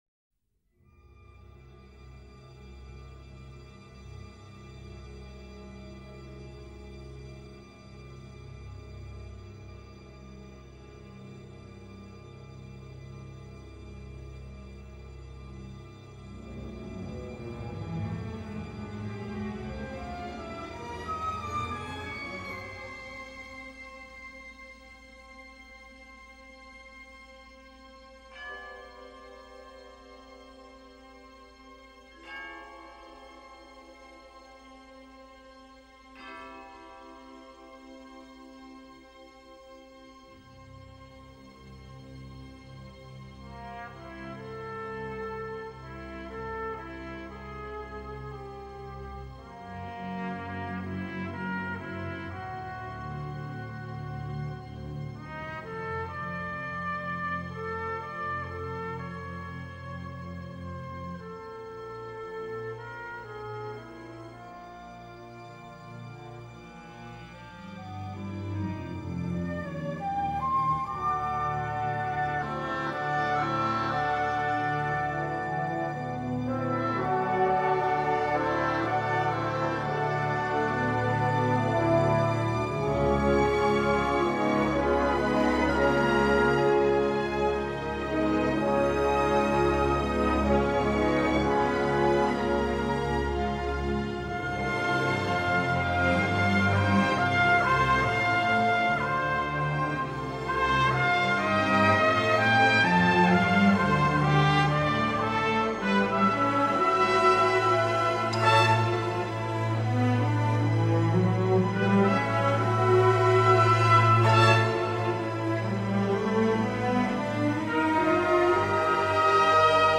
bleak and dissonant soundscape